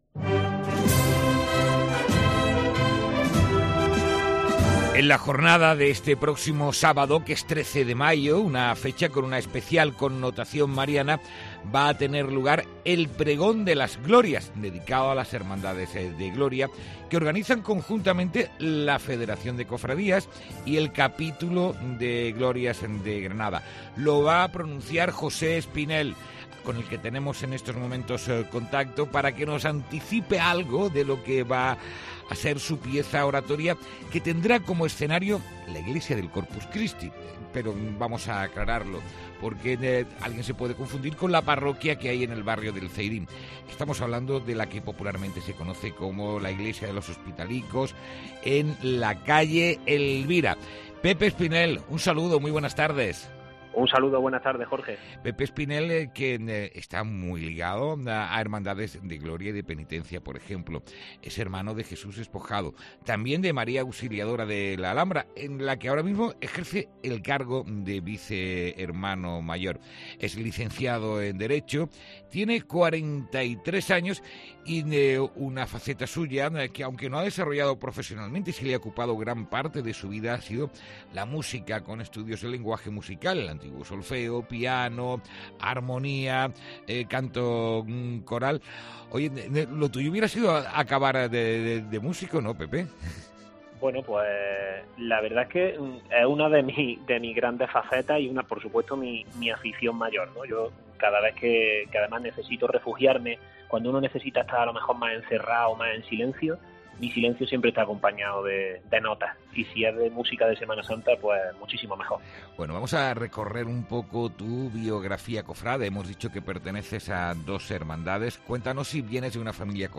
Santa Fe PREGÓN ENTREVISTA